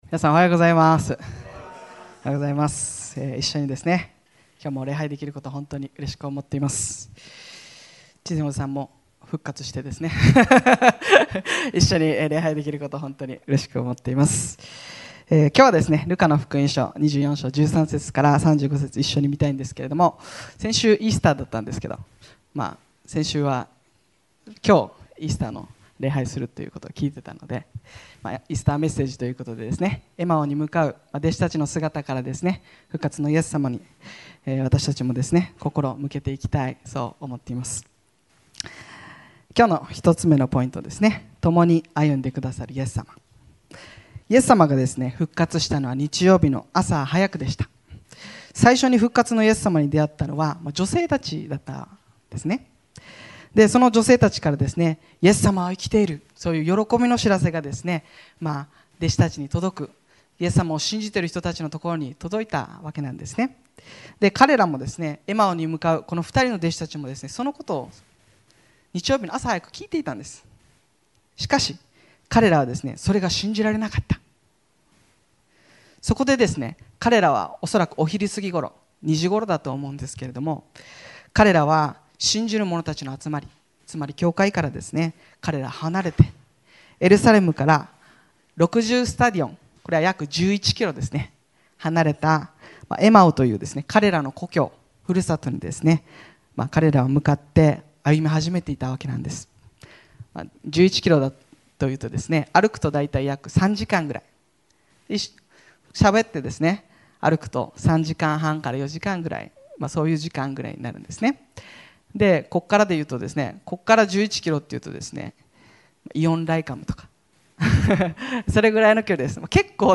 2026年4月12日礼拝メッセージ